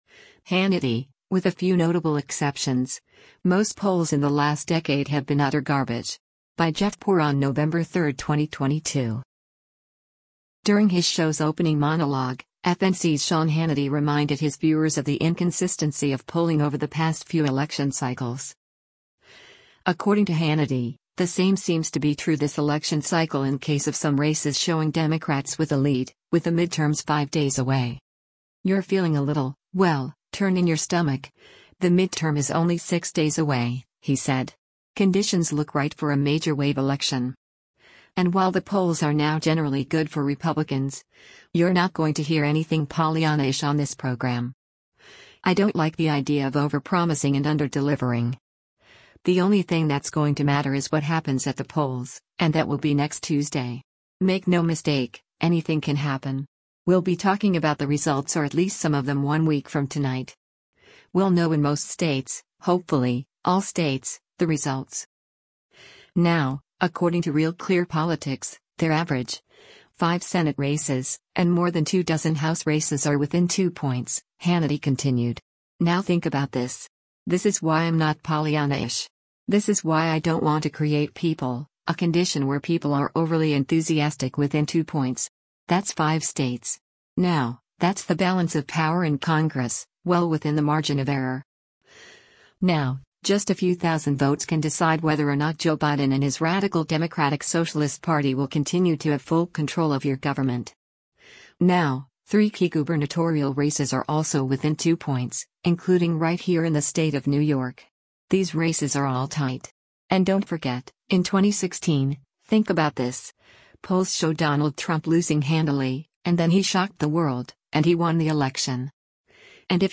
During his show’s opening monologue, FNC’s Sean Hannity reminded his viewers of the inconsistency of polling over the past few election cycles.